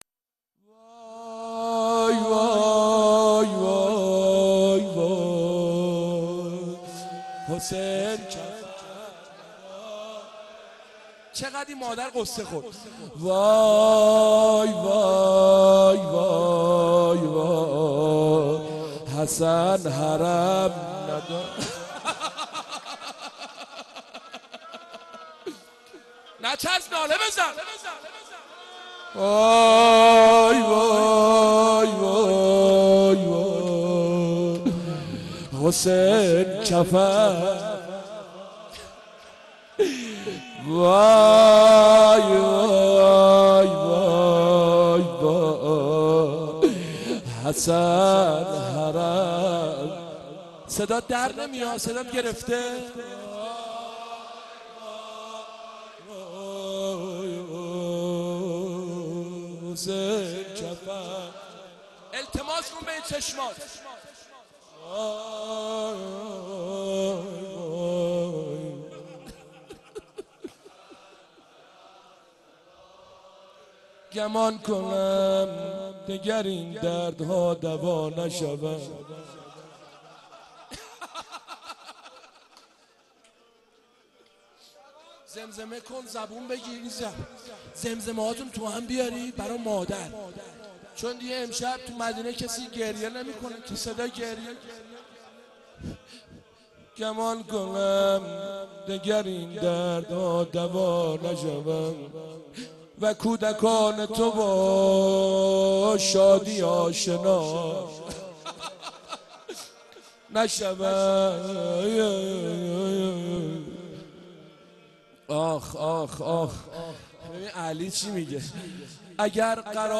مناجات امام زمان